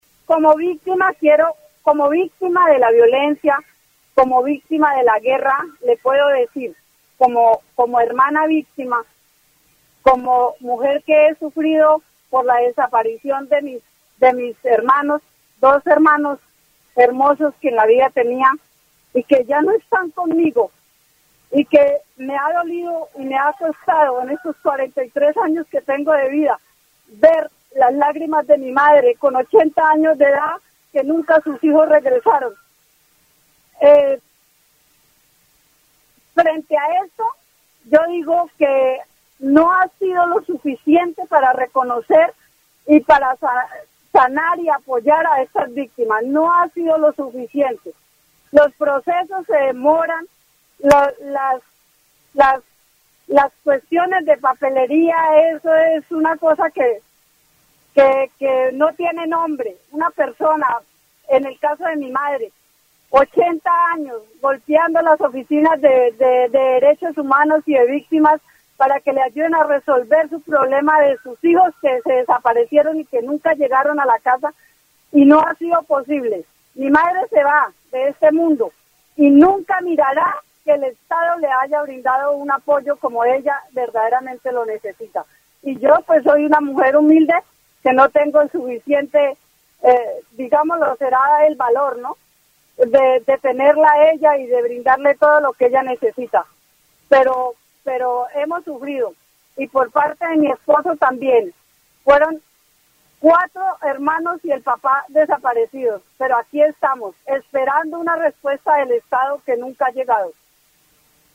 En este programa, una víctima de la violencia narra su experiencia personal y la de su familia, resaltando la falta de apoyo del Estado y la burocracia en los procesos de asistencia a las víctimas.
Vichada (Región, Colombia) -- Grabaciones sonoras , Programas de radio , Víctimas de la violencia , Asistencia estatal a víctimas del conflicto , Burocracia y acceso a la justicia , Impacto del conflicto armado en familias